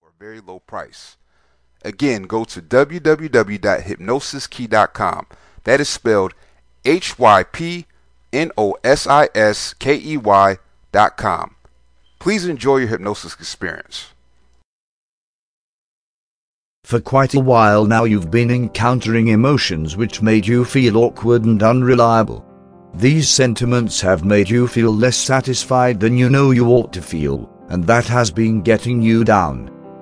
Jealousy Self Hypnosis Mp3